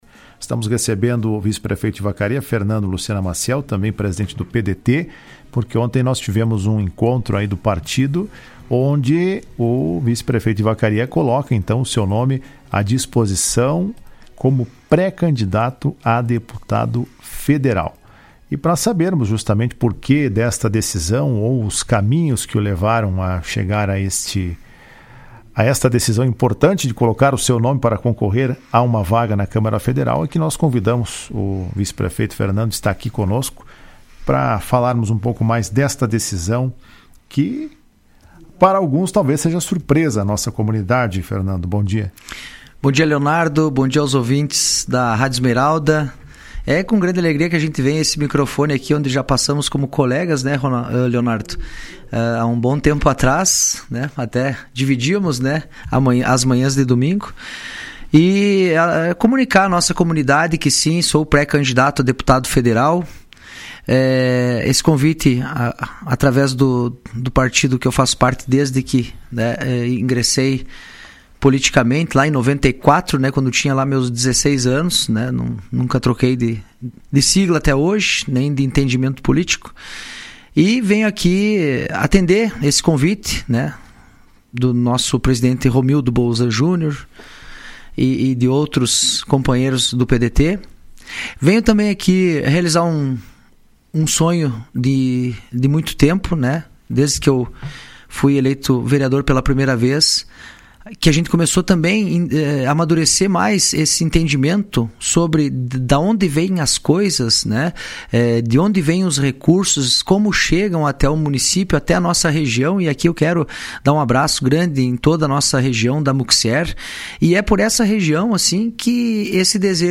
Em entrevista ao programa Comando Geral desta terça-feira, ele disse que tomou a decisão após um convite de seu partido e também pela necessidade de a região possuir um representante na câmara federal.